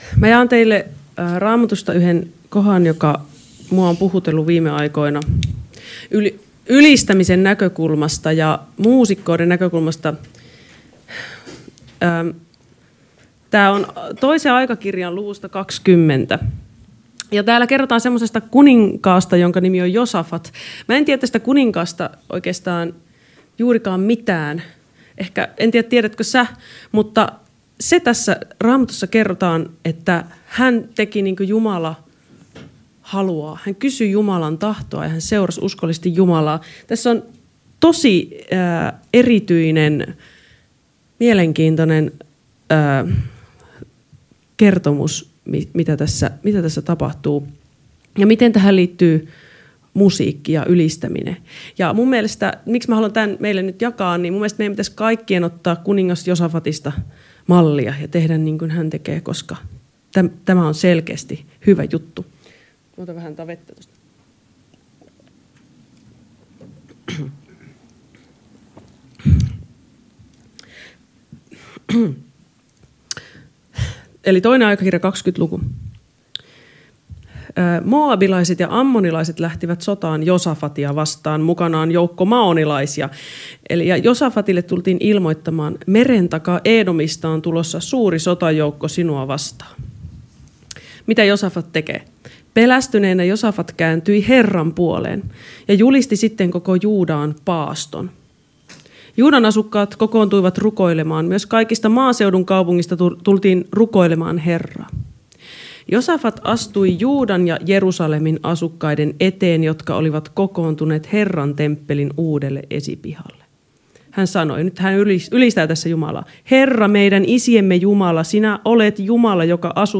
puhe_hema.mp3